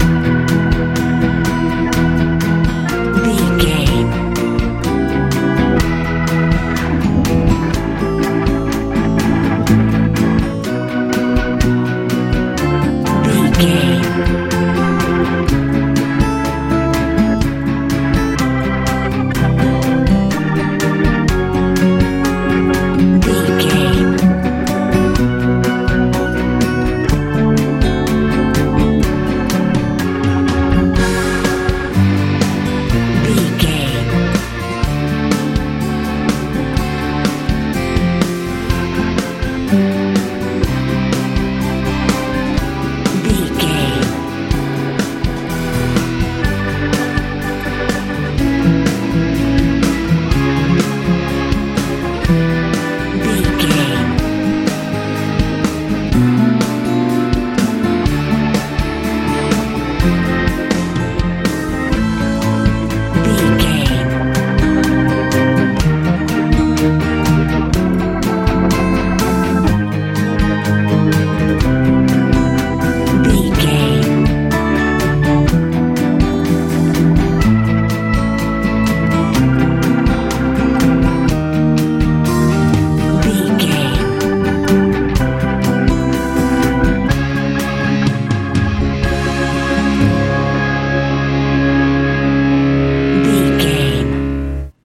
easy rock
Ionian/Major
light
mellow
organ
electric guitar
acoustic guitar
bass guitar
drums
pompous
confident